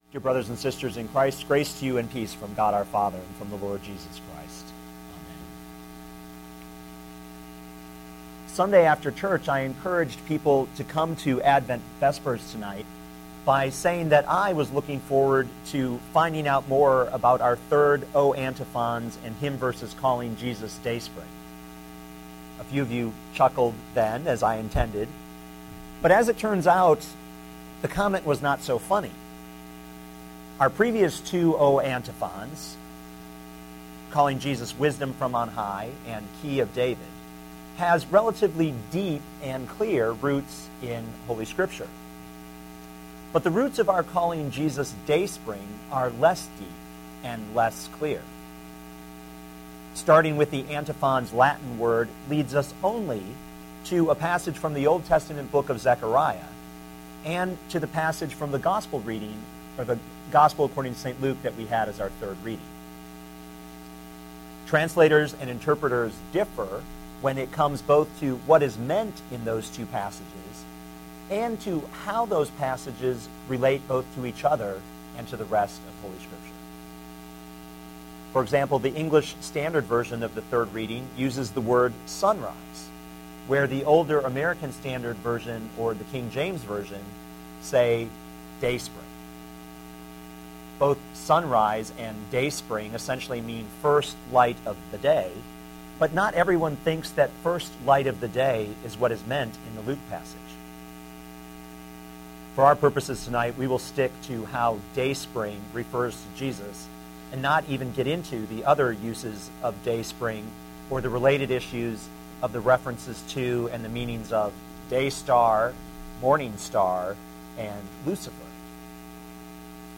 Dear Brothers and Sisters in Christ,